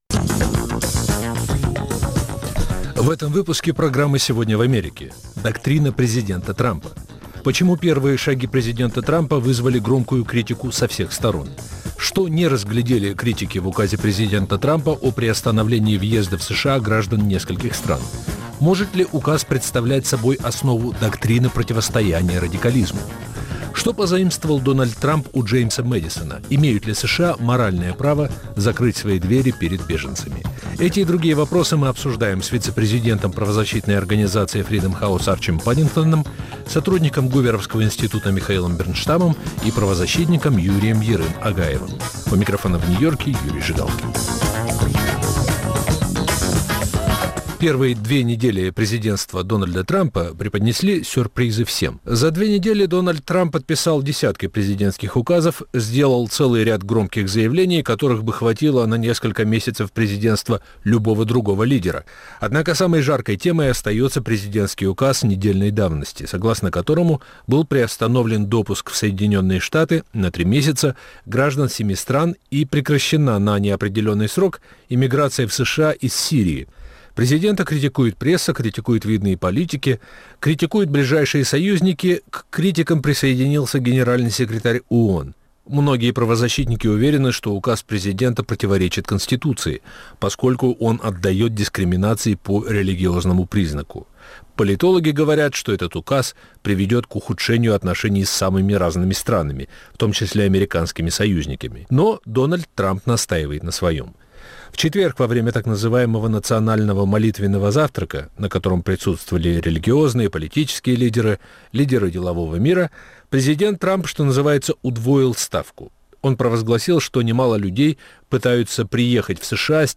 обсуждают американские эксперты.